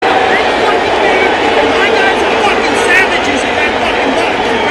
aaron-boone-gets-ejected-and-gives-a-great-rant-a-breakdown-mp3cut.mp3